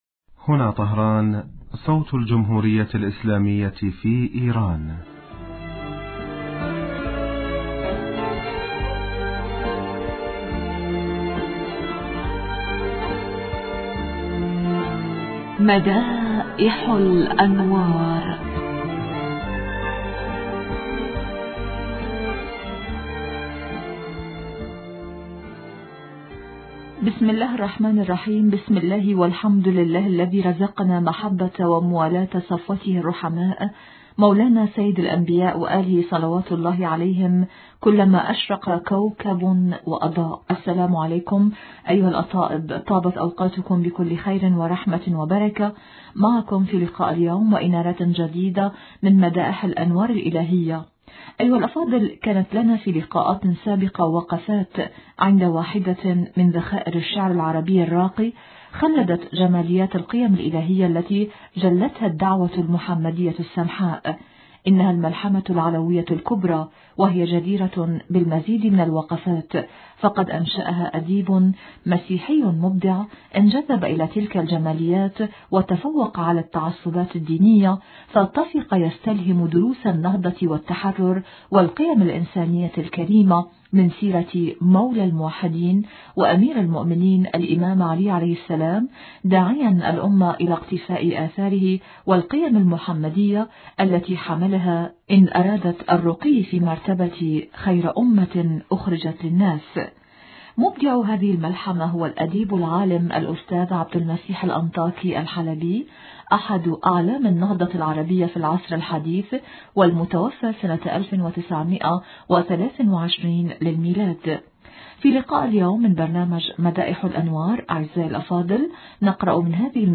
في لقاء اليوم من برنامج (مدائح الأنوار) نقرأ من هذه الملحمة التي قاربت أبياتها الستة آلاف بيت، طائفة من الأبيات التي يمدح فيها هذا الأديب حامي الرسول وناصره – صلى الله عليه وآله – مولانا أبي طالب مستلهماً من مواقفه – عليه السلام – جمال قيم نصرة الحق ومؤازرته في جميع الأحوال؛ تابعونا على بركة الله.